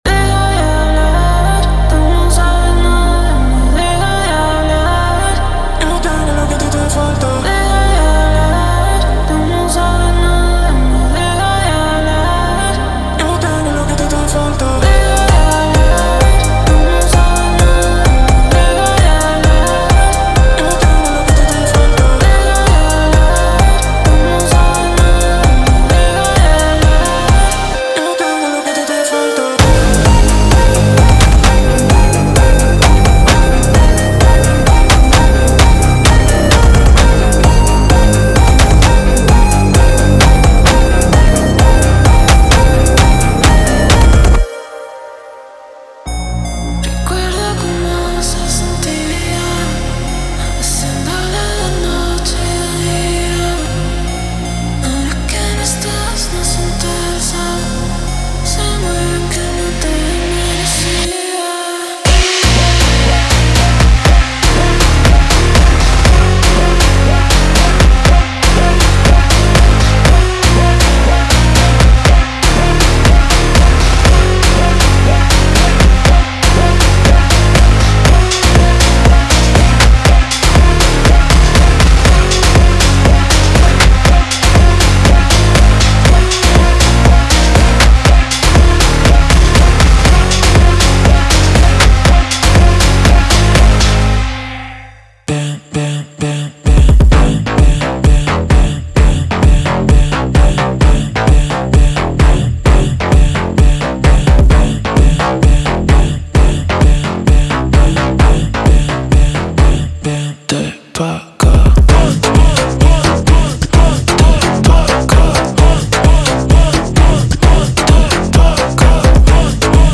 Type: Templates Samples
Phonk Vocals
Introducing a new genre with Spanish vocals
07 Vocal Hooks Wet
15 Drum Loops